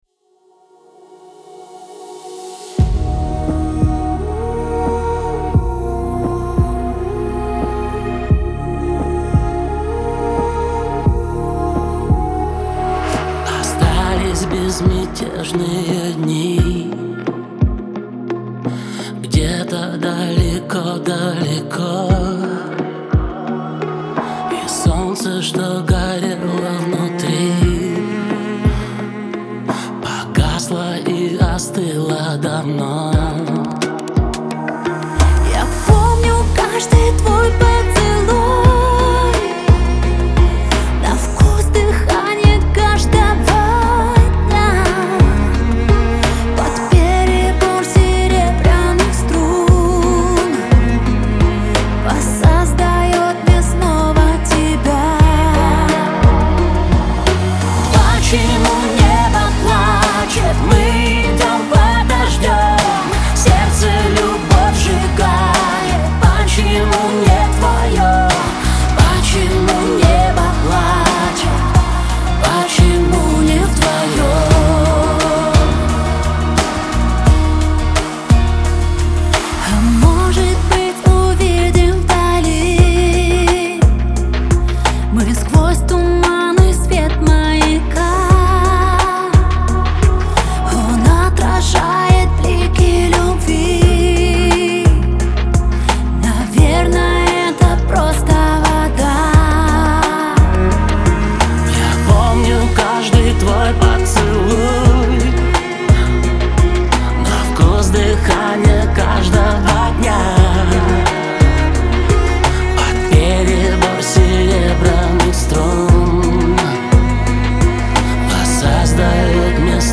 Трек размещён в разделе Русские песни / Альтернатива.